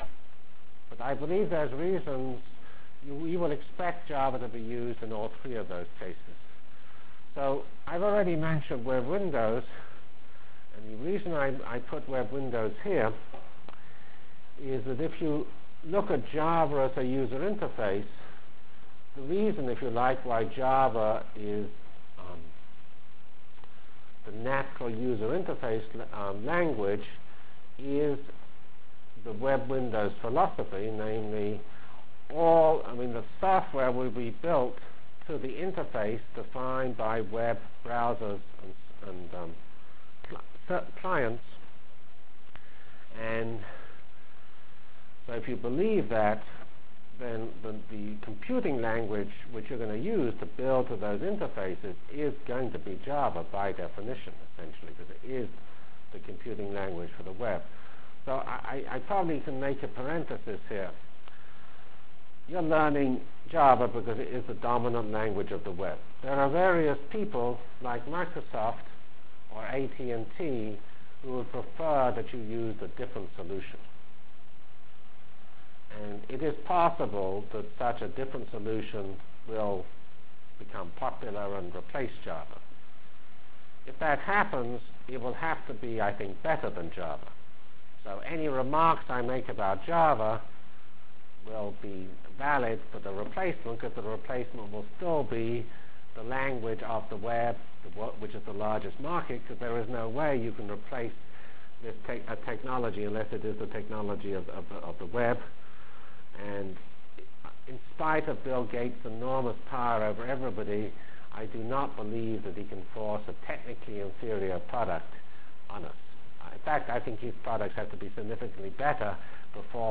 From Feb 5 Delivered Lecture for Course CPS616